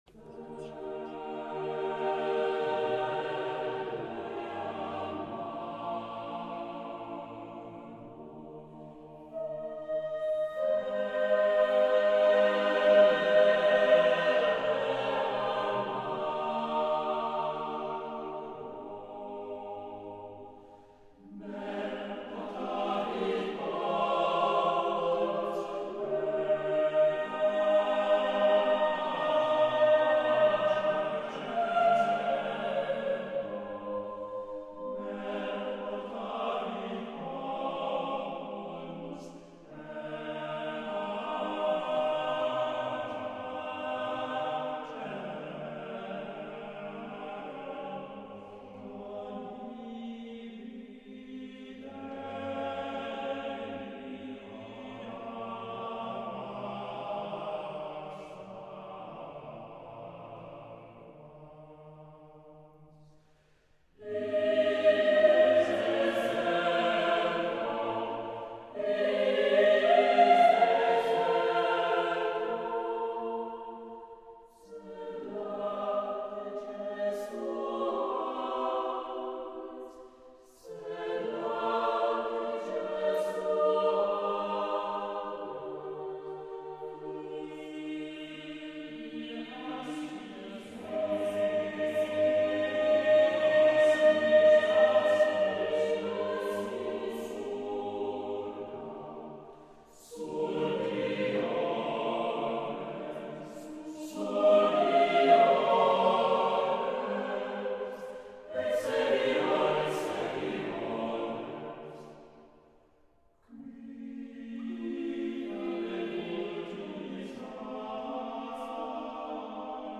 Questa antica tecnica di riscrittura testuale, assai diffusa nel Rinascimento e nel primo Barocco, permette di riscoprire capolavori noti sotto una nuova luce spirituale, offrendo un’esperienza d’ascolto intensa e sorprendente. Diego Fasolis sarà ai microfoni di Rete Due , per presentarci il concerto.